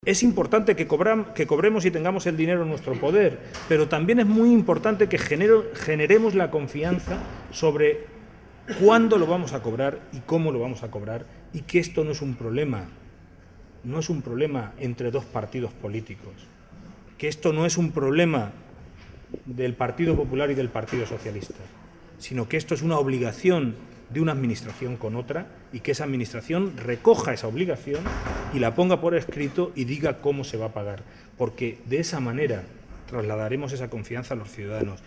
Audio alcalde Puertollano-4